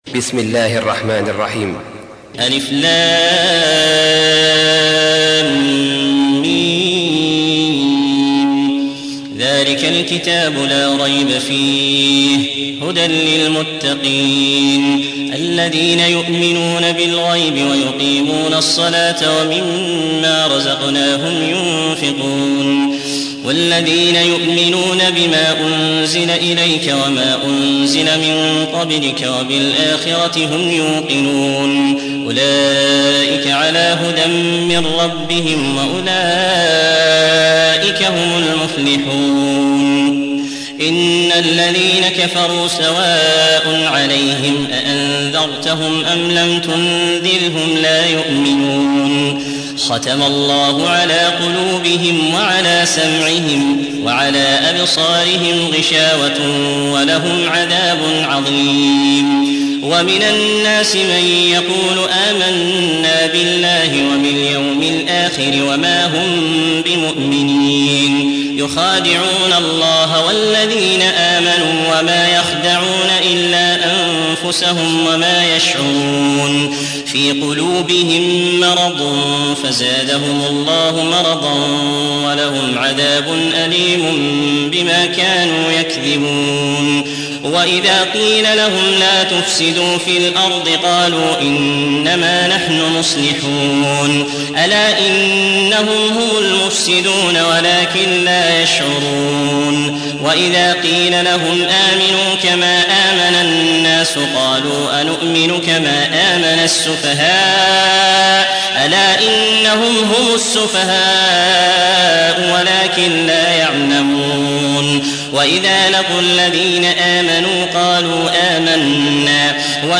2. سورة البقرة / القارئ